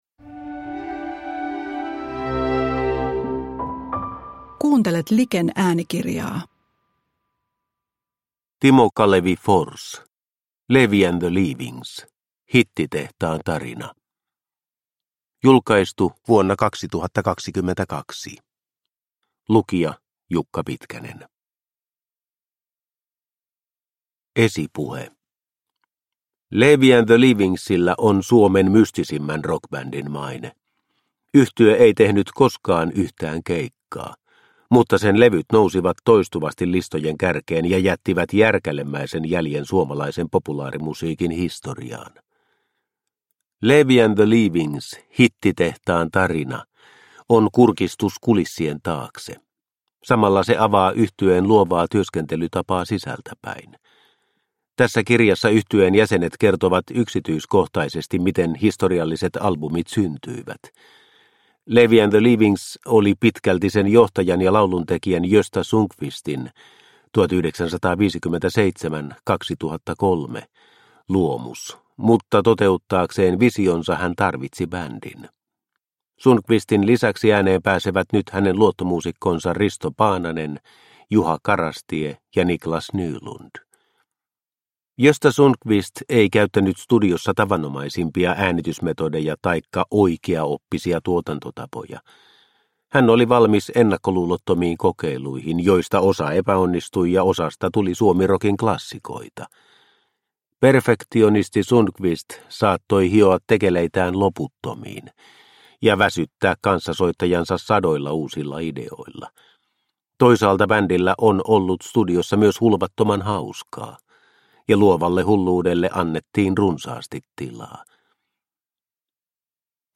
Leevi and the Leavings – Ljudbok – Laddas ner